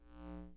描述：我把话筒靠近MOTU 828mkII的显示屏，得到了一种数字嗡嗡声
标签： 嗡嗡声 数字 哼哼
声道立体声